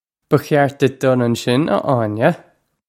Pronunciation for how to say
Buh khyart ditch dull un-shin, uh Awn-yeh!
This is an approximate phonetic pronunciation of the phrase.